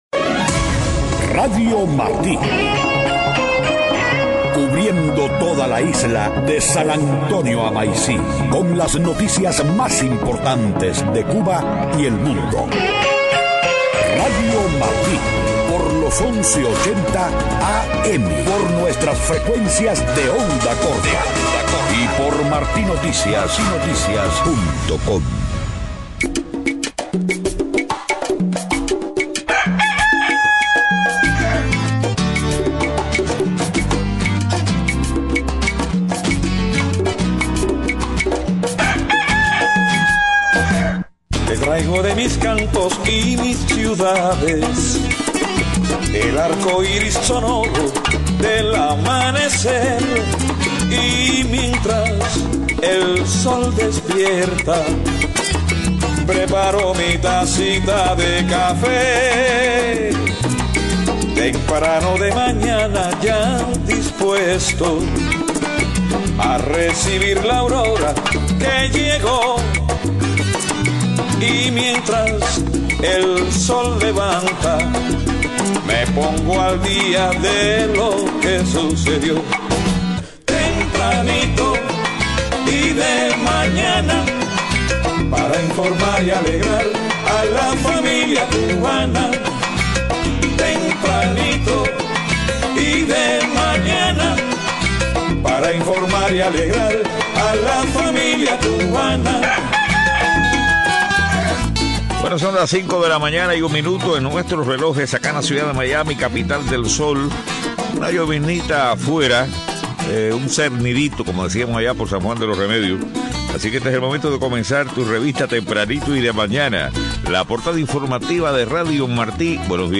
5:00 a.m. Noticias: Grupo opositor cubano pide al canciller español que exija el respeto a los derechos humanos durante su visita a Cuba. Presidente Obama anuncia medidas ejecutivas que podrían detener la deportación de millones de inmigrantes ilegales. Manifestantes en México bloquean avenida del aeropuerto internacional en protestas por 43 estudiantes desaparecidos.